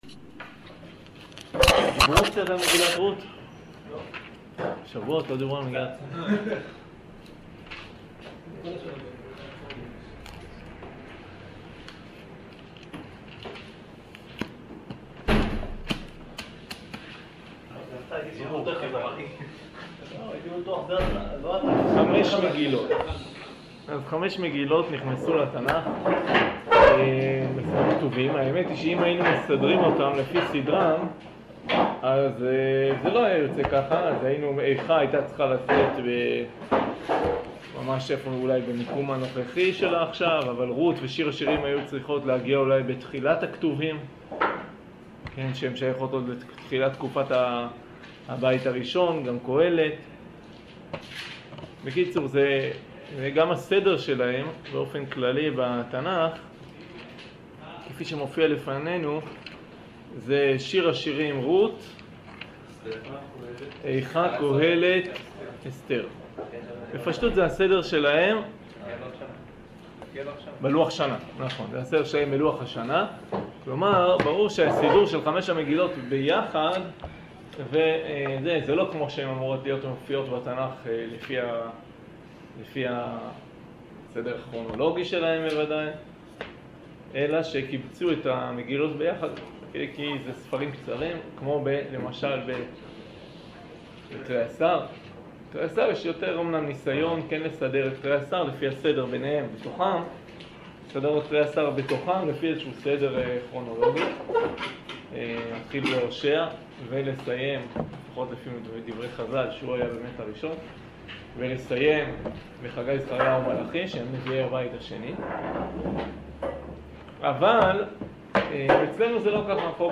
שיעור מבוא למגילת רות